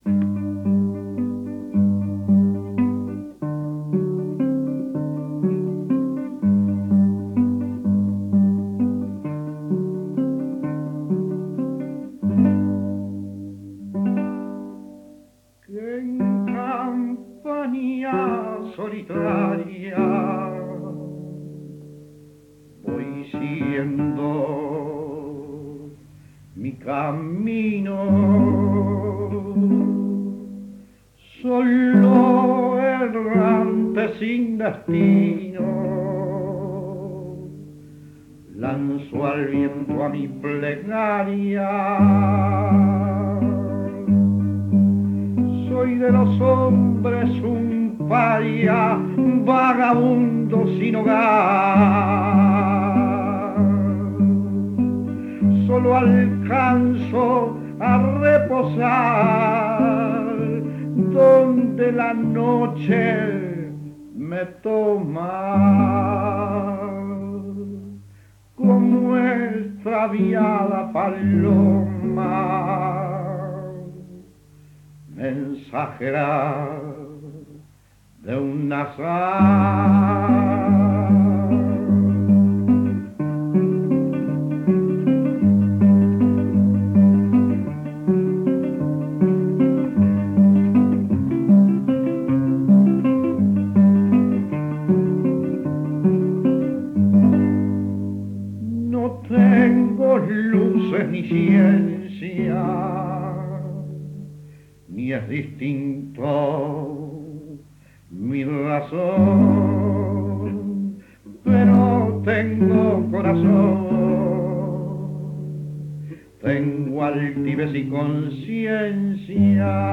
canto y guitarra
Formato original de la grabación: cinta magnética